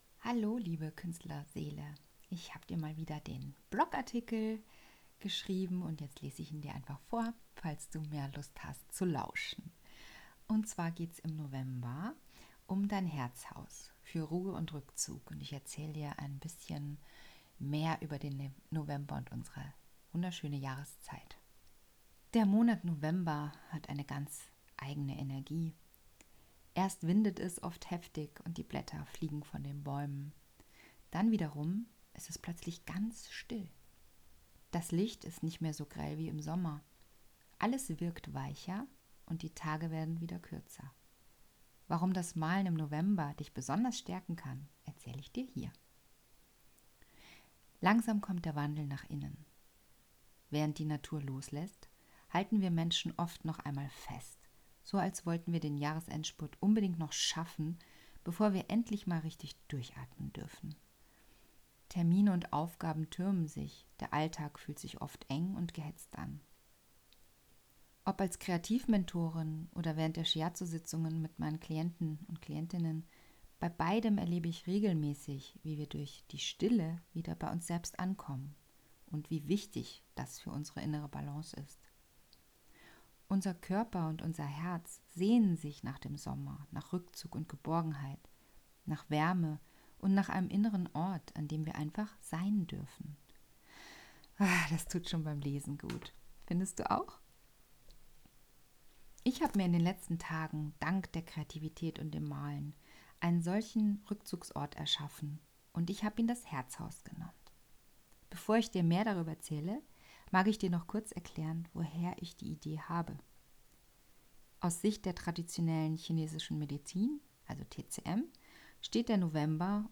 Falls du Lust hast dir den Blog-Artikel von mir vorlesen zu lassen, klick einfach auf diese Datei: